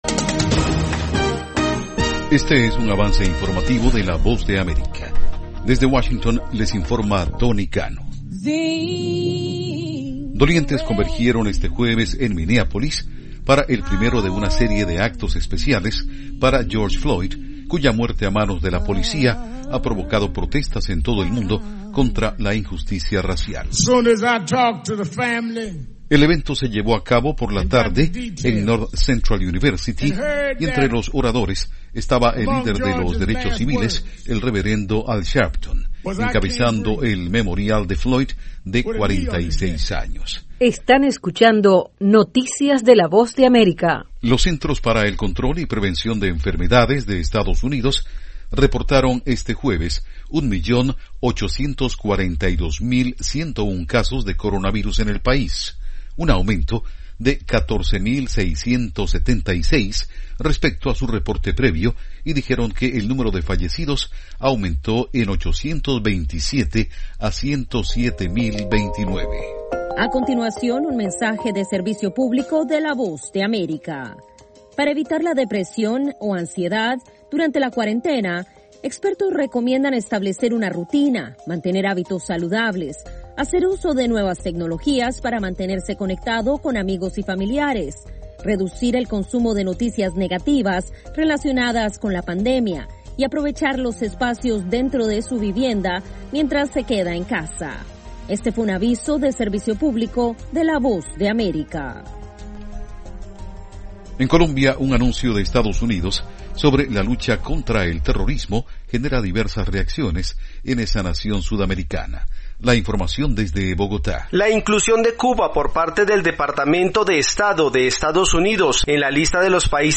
Algunas de las noticias de la Voz de América en este avance informativo: